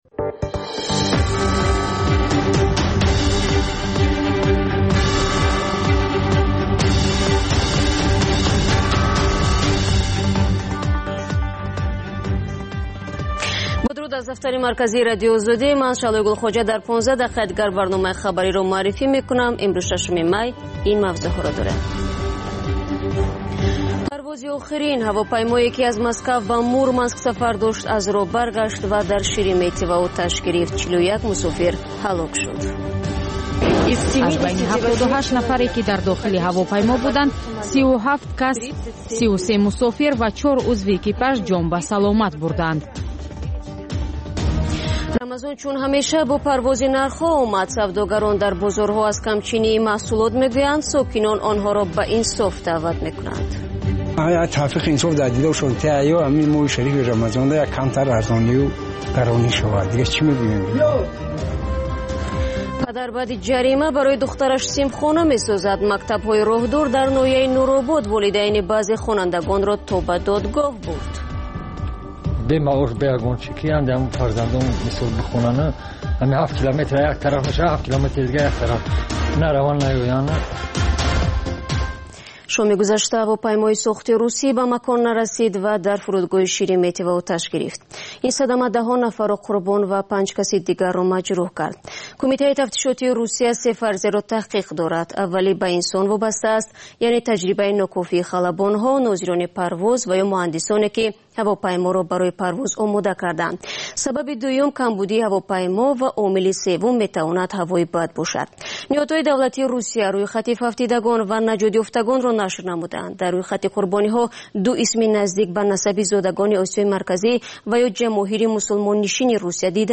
Маҷаллаи хабарӣ